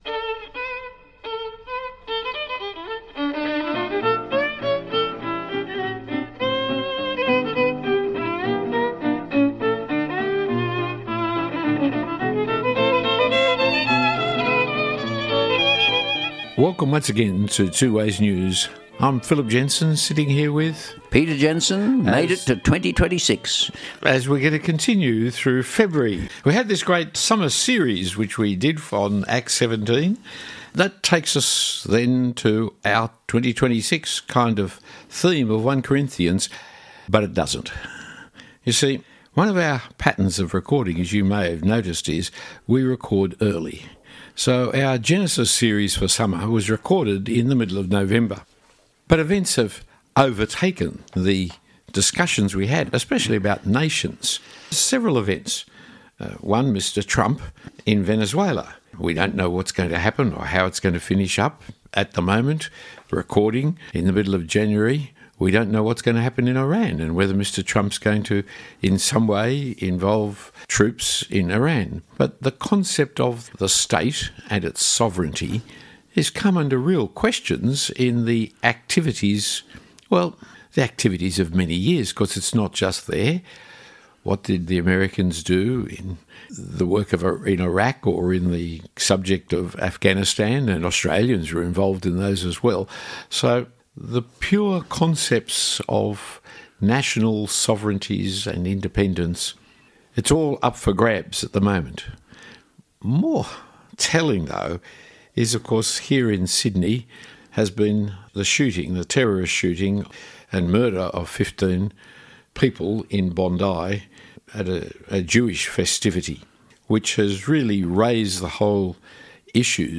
This podcast is an important discussion on the Bondi shooting attack of 15 December 2026 and antisemitism.